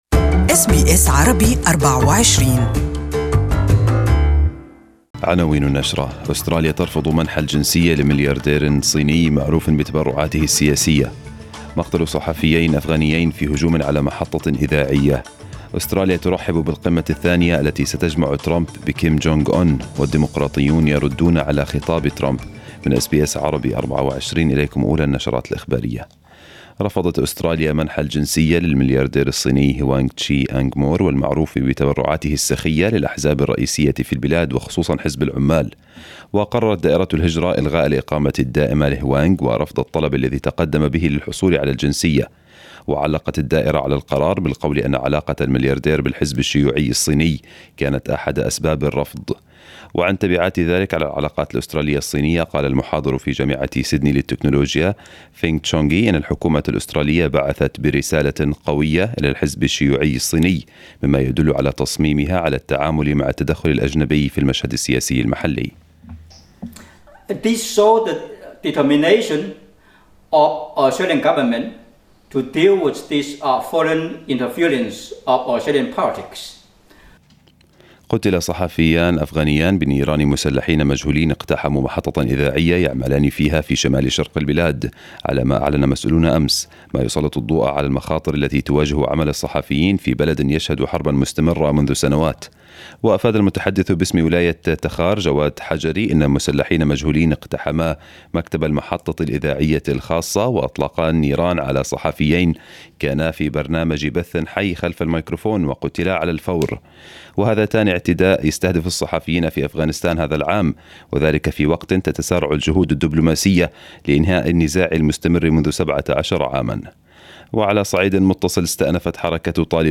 نشرة الأخبار باللغة العربية لهذا الصباح